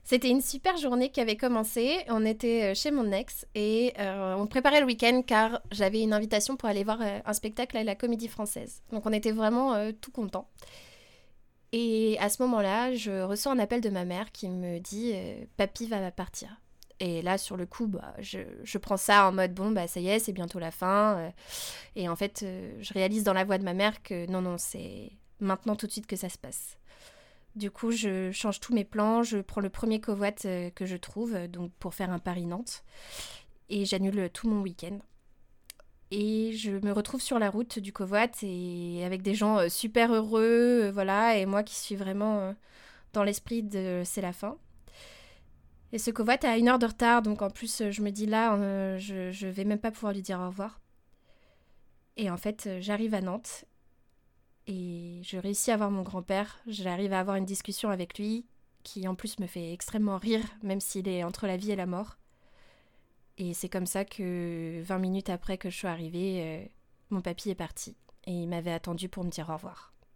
Voix Témoignage